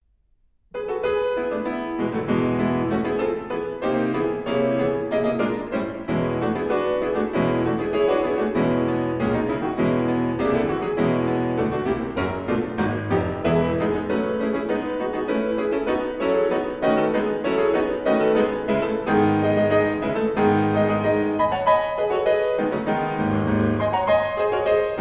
in E flat minor: Poco lento, serioso